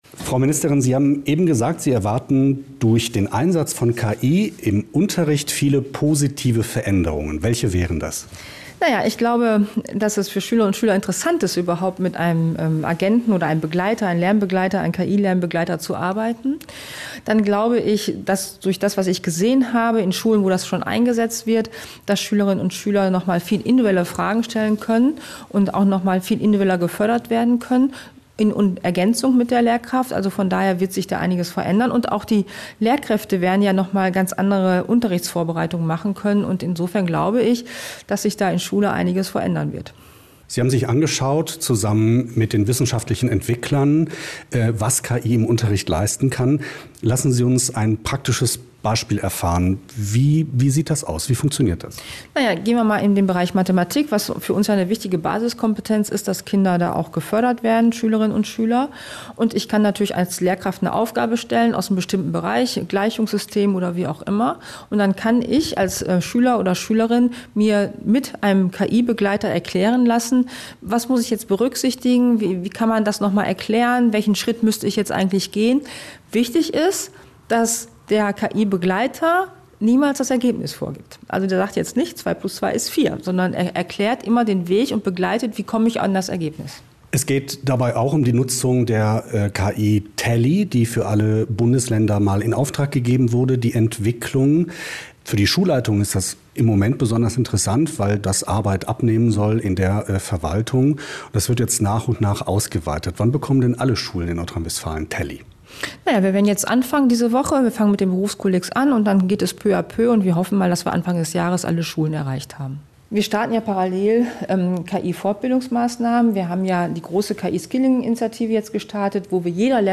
Landesschulministerin Feller hat jetzt Konzepte vorgestellt, wie sie sich den Einsatz von KI genau vorstellt. Wir haben mit ihr und mit einem der wissenschaftlichen Entwickler dieser Konzepte darüber gesprochen.
interview_ministerin_feller_zu_ki_an_schulen.mp3